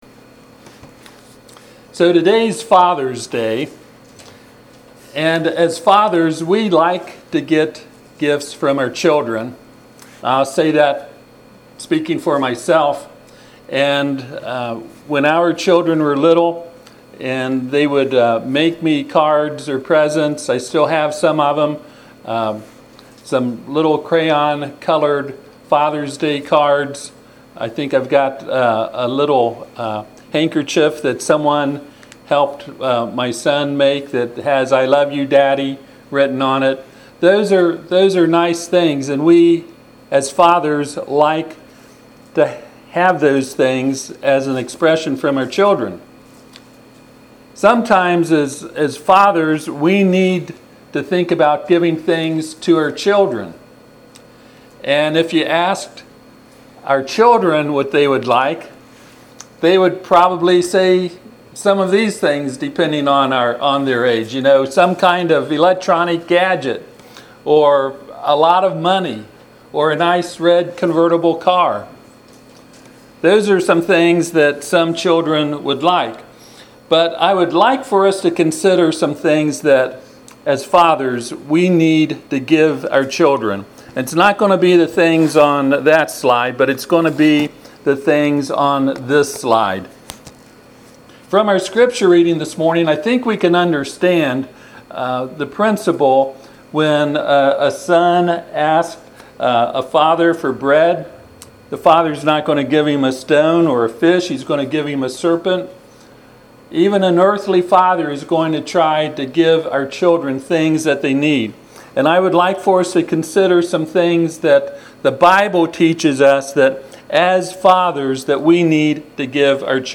Passage: Matthew 7;9-12 Service Type: Sunday AM « Establishing Authority.